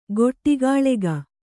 ♪ goṭṭigāḷega